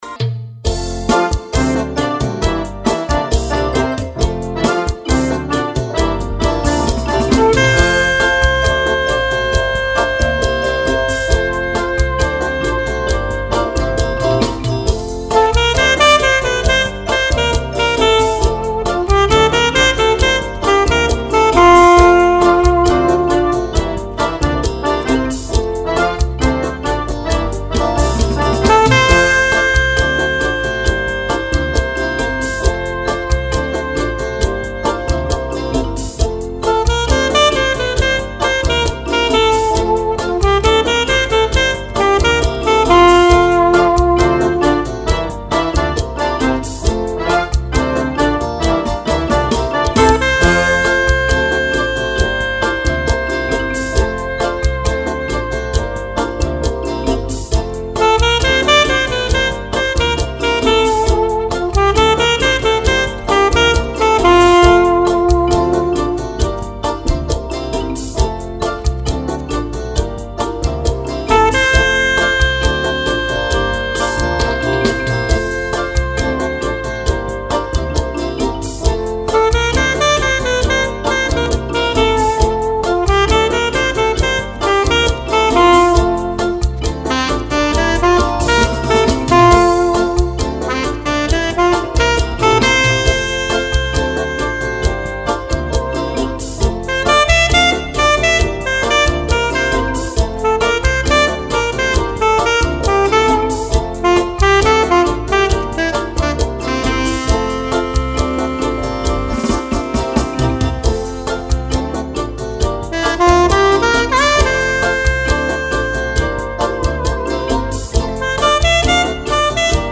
845   04:27:00   Faixa:     Bolero